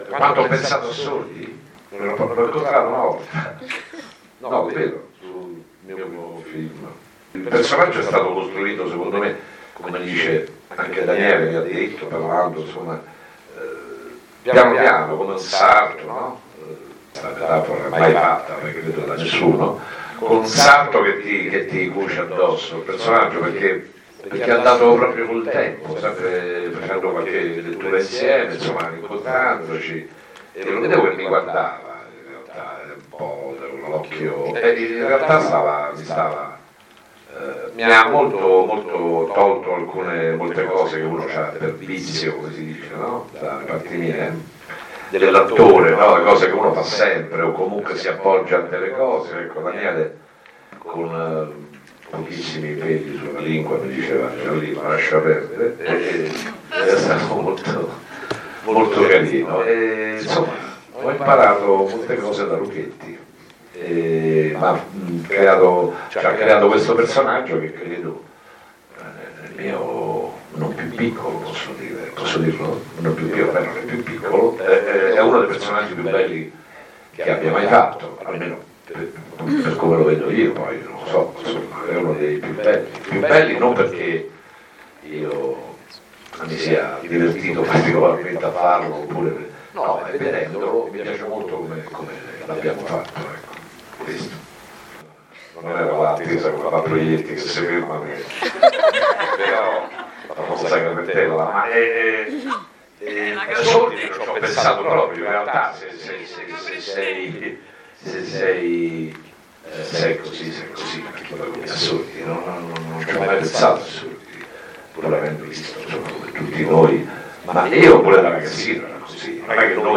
io-sono-tempesta-marco-giallini-parla-del-suo-ruolo.mp3